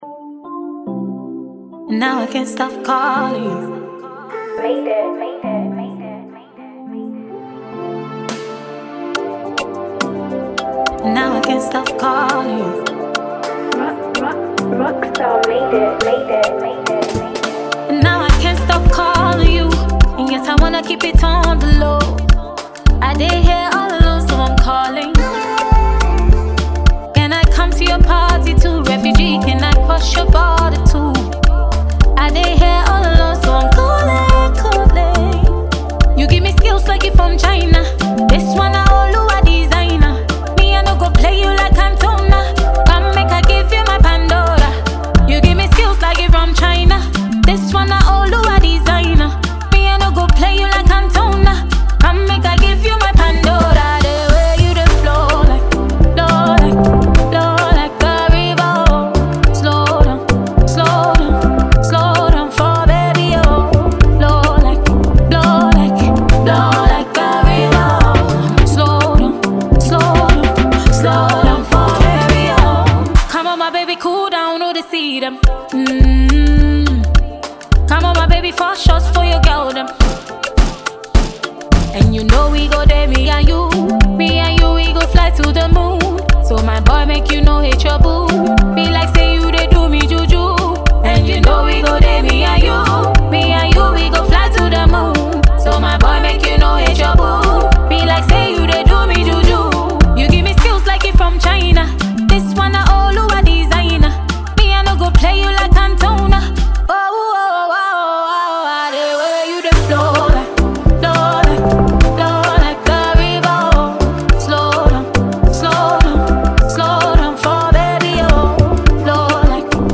a Ghanaian female singer